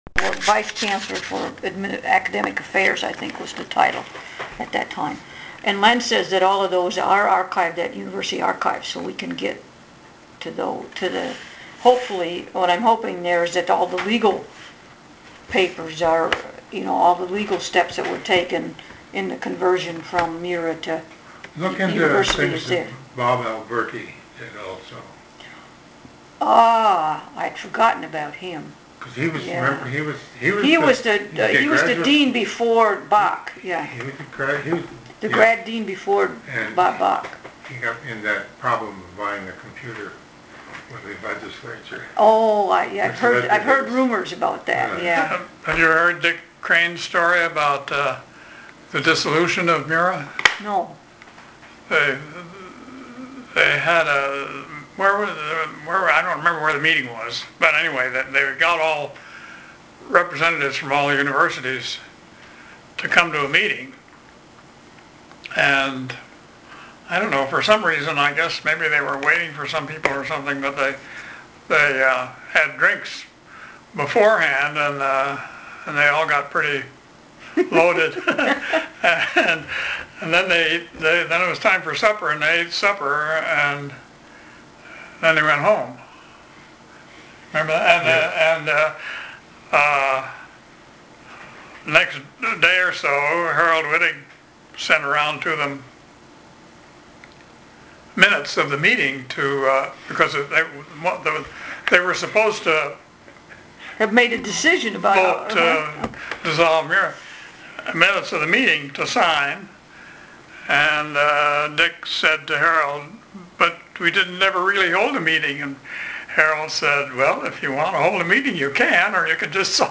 Recording, oral